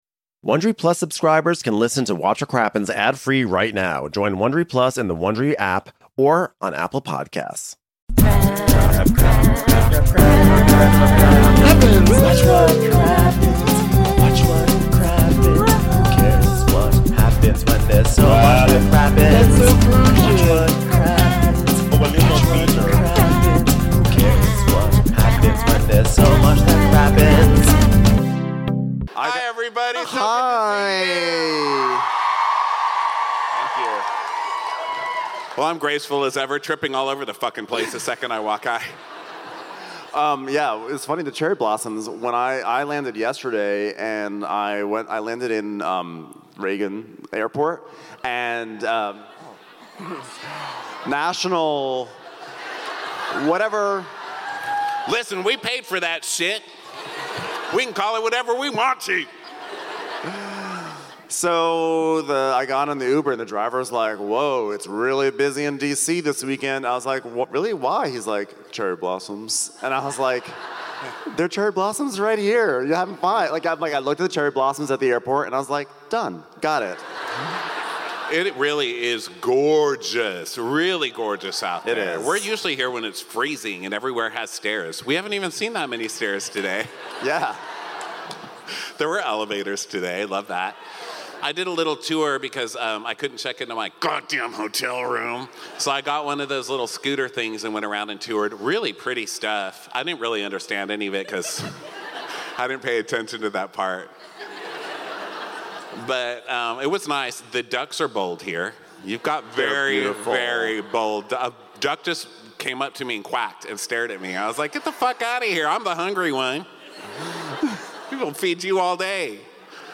#2780 Southern Charm Reunion S10E16: Tears on My Pillow - Live in DC
We’re live in DC to cover the Southern Charm Reunion Part One! Craig cries about Paige, Shep and Molly talk about bangin, and Venita and JT get into a very confusing timeline fight.